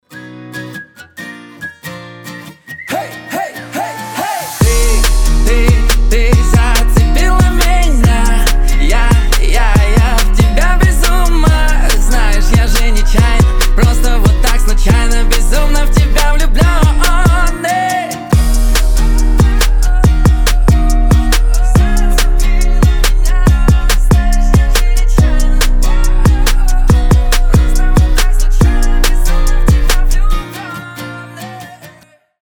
• Качество: 320, Stereo
гитара
свист
легкие